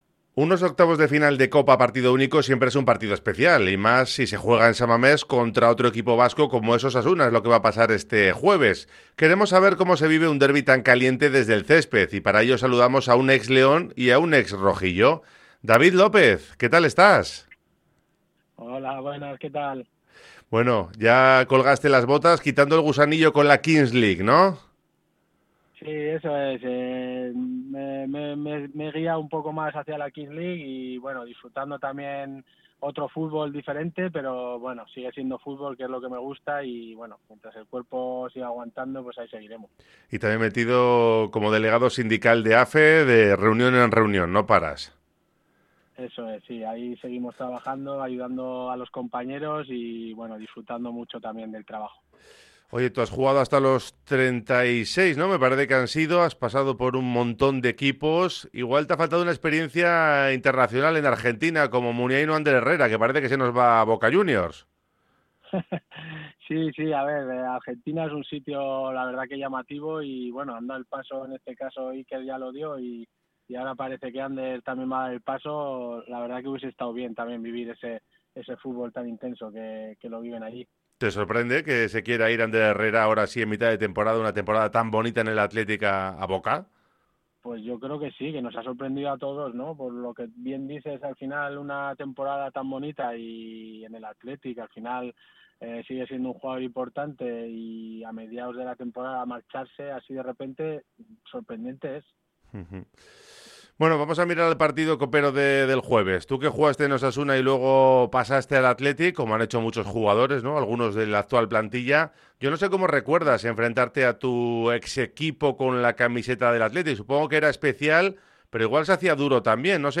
ha compartido sus impresiones sobre el derbi vasco en los octavos de final de la Copa del Rey y ha comentado la sorprendente salida de Ander Herrera a Boca Juniors durante una entrevista concedida al programa Oye Cómo Va de Radio Popular-Herri Irratia.